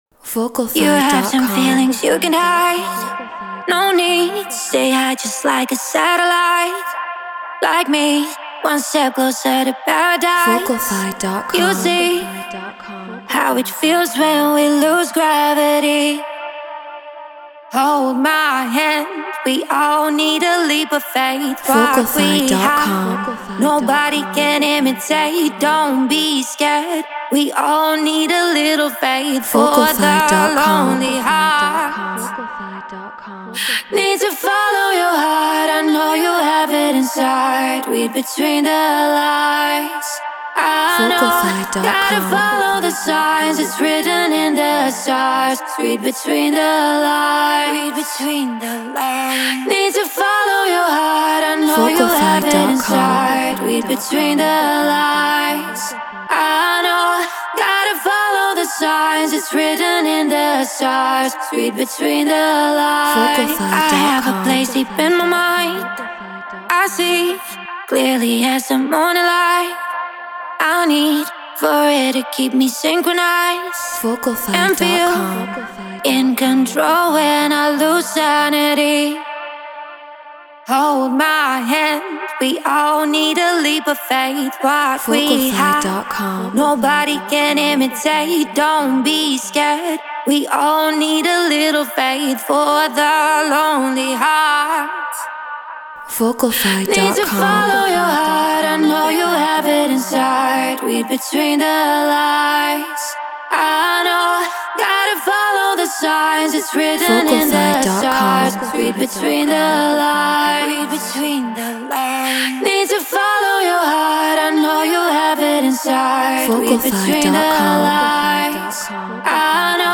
House 122 BPM Cmin
Treated Room